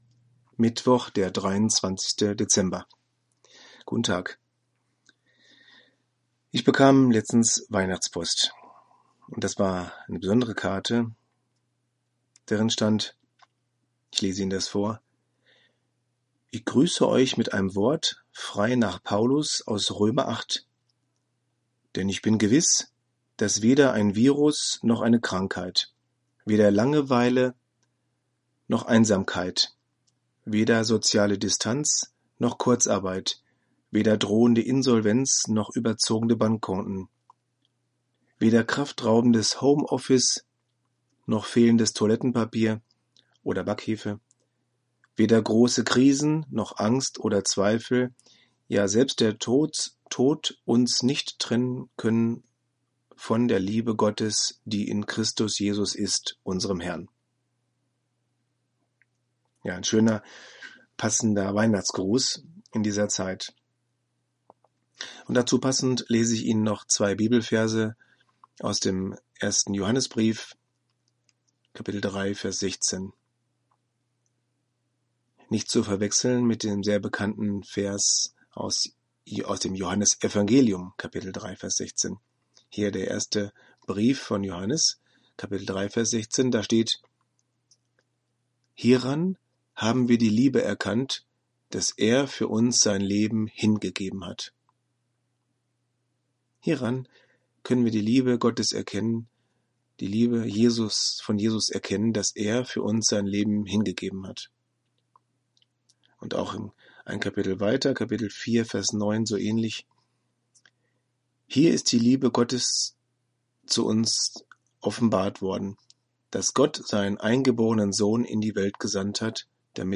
Audio-Andachten: Impulse, Denkanstöße über Gott und die Welt